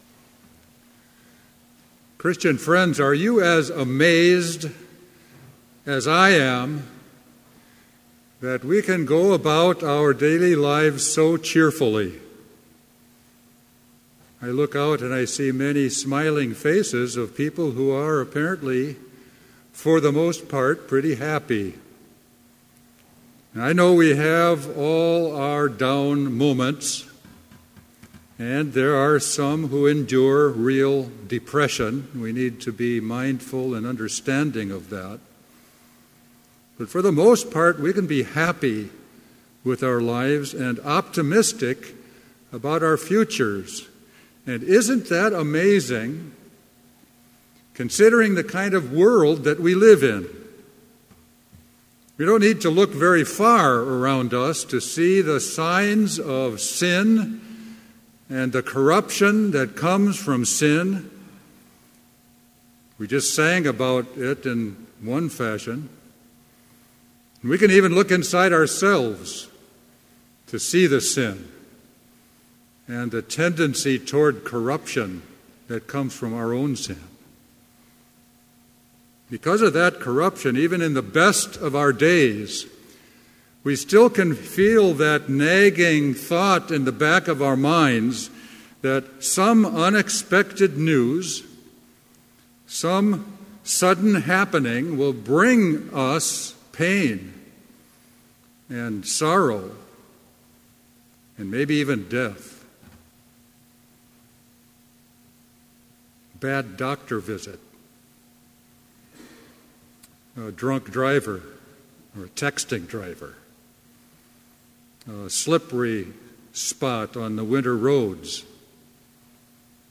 Complete service audio for Chapel - November 3, 2016
Prelude Hymn 555, Rise Again, Ye Lion-Hearted Reading: Revelation 21:1-4 Devotion Prayer Hymn 555, vv. 3 & 4, Great of heart… Blessing Postlude